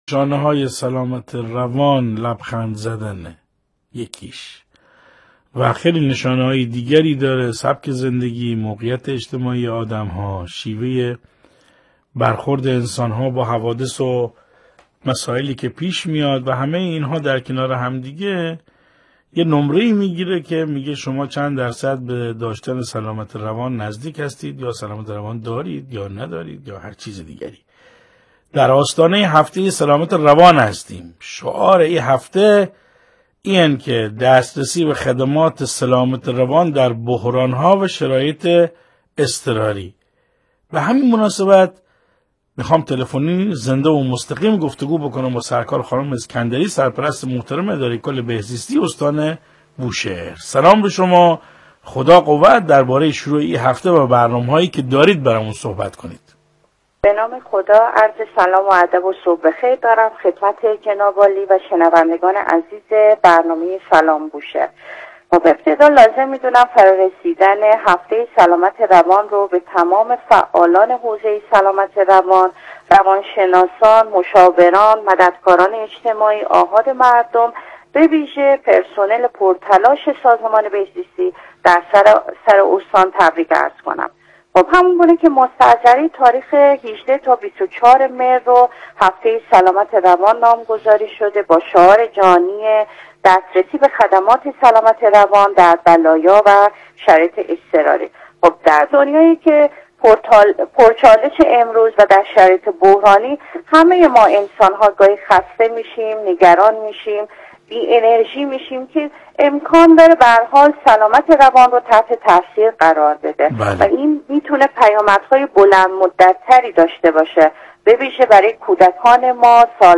بشنویم| سرپرست بهزیستی استان بوشهر در گفتگو با رادیو: سلامت روان به اندازه سلامت جسم اهمیت دارد/ تشریح برنامه‌های بهزیستی به مناسبت هفته سلامت روان
مهرناز اسکندری، سرپرست اداره کل بهزیستی استان بوشهر، در یک گفتگوی تلفنی زنده با برنامه پرمخاطب صبحگاهی صدا و سیمای مرکز بوشهر، به مناسبت فرا رسیدن هفته سلامت روان (۱۸ تا ۲۳ مهر)، ضمن تبریک این هفته به فعالان حوزه سلامت روان، بر اهمیت حیاتی این موضوع تأکید کرد و برنامه‌های سازمان بهزیستی را تشریح نمود.